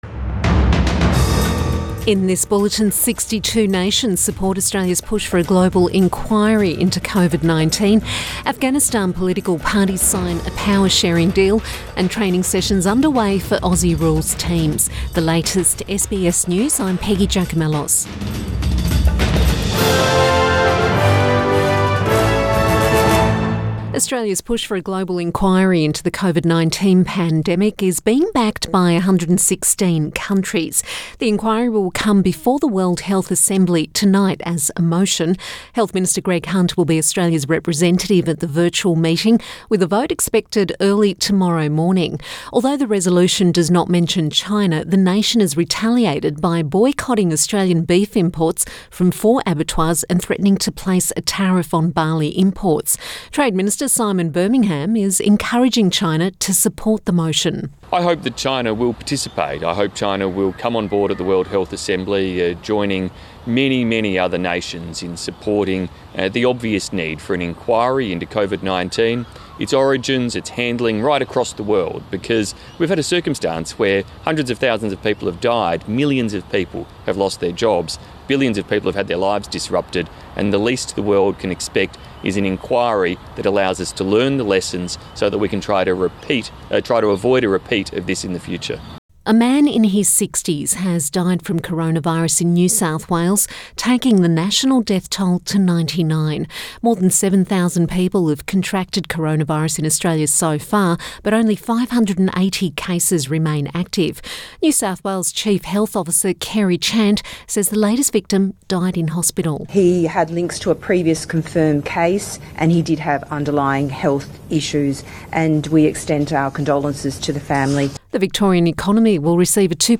Midday bulletin May 18 2020